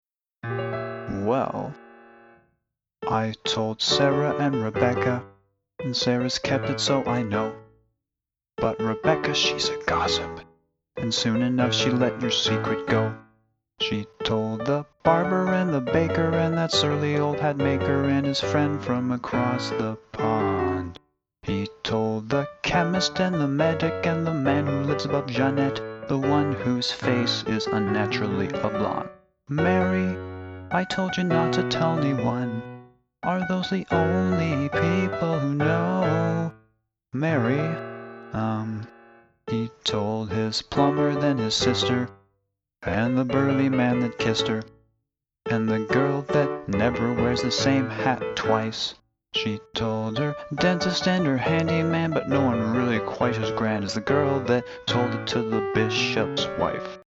In the first workshop I played for the first time a piano arrangement of lyrics written by one of the students, which I had worked on during the weekend before.
For today, I made a quick ‘scratch vocal’ recording of the song, which is posted below with the lyrics.